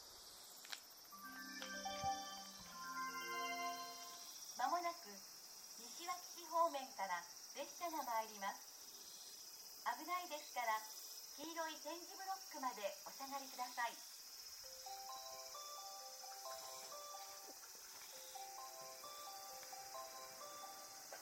この駅では接近放送が設置されています。
接近放送普通　谷川行き接近放送です。